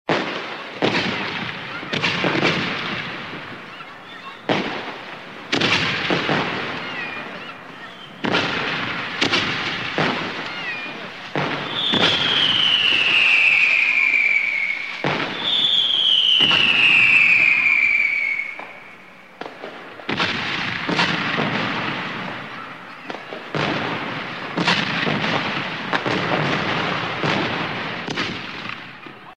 phao-hoa.mp3